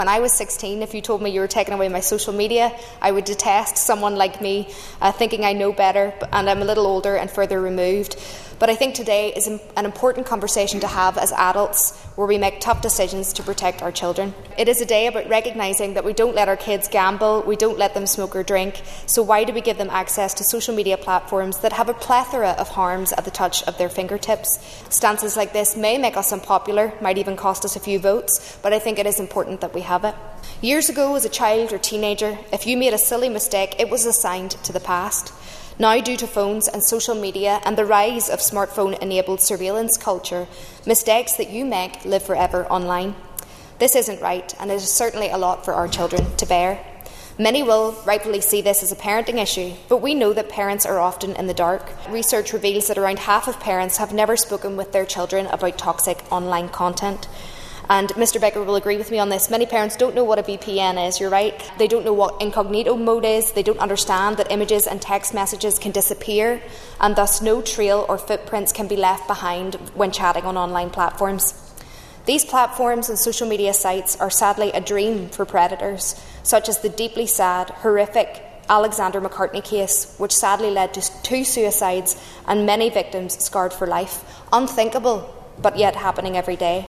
Moving the original motion, East Derry MLA Cara Hunter said such a discussion will not be popular with many young people, but it’s vital that the conversation is had………